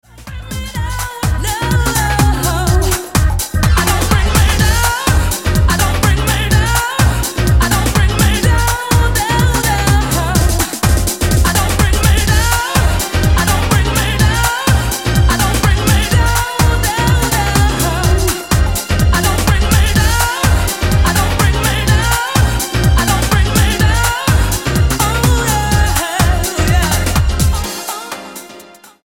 STYLE: Dance/Electronic
2. (Original Club Mix)